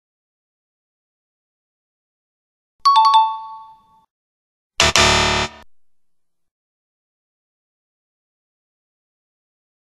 دانلود صدای جواب درست و غلط 2 از ساعد نیوز با لینک مستقیم و کیفیت بالا
جلوه های صوتی